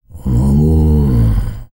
TUVANGROAN12.wav